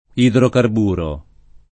idrocarburo
idrocarburo [ idrokarb 2 ro ]